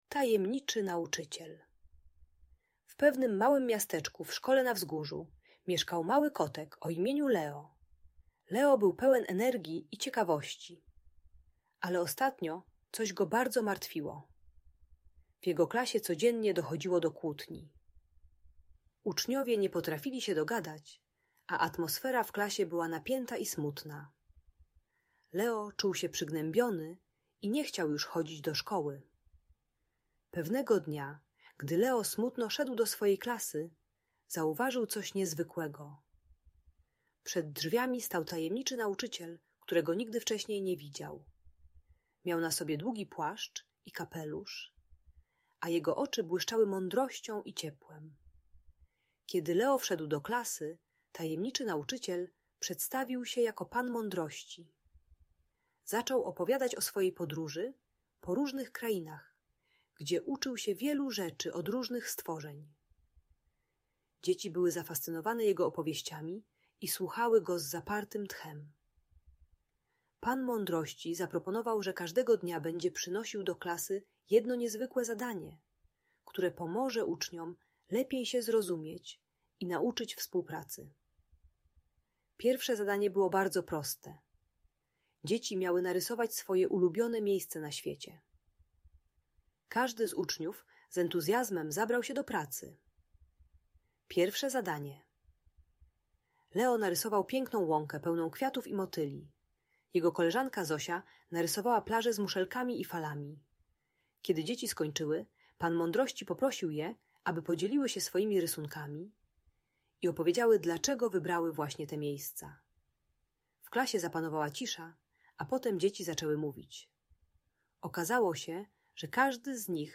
Tajemniczy Nauczyciel: Wzruszająca Historia o Współpracy - Audiobajka